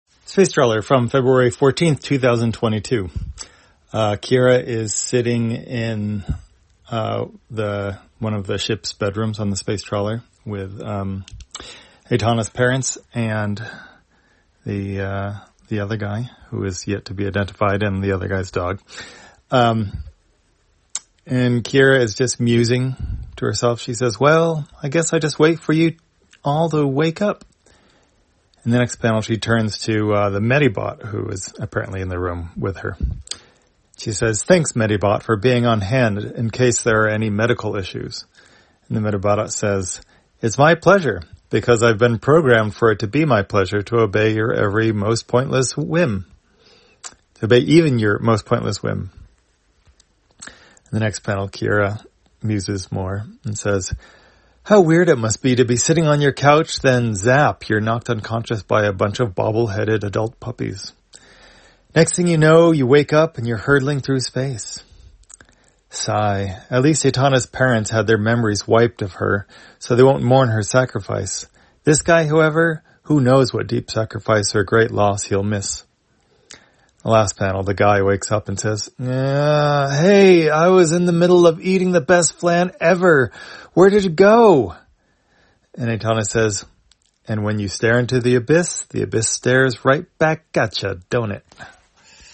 Spacetrawler, audio version For the blind or visually impaired, February 14, 2022.